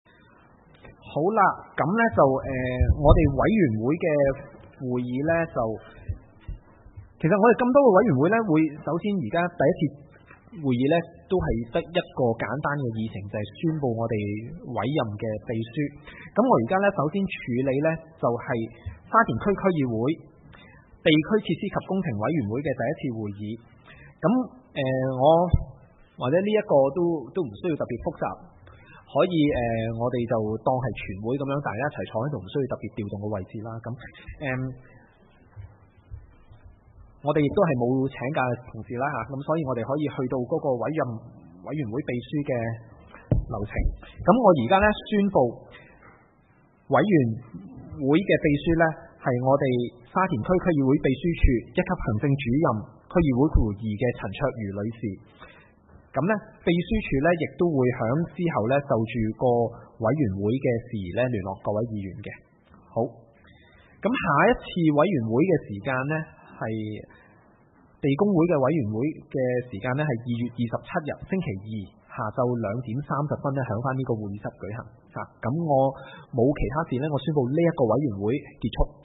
沙田區議會 - 會議的錄音記錄